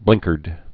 (blĭngkərd)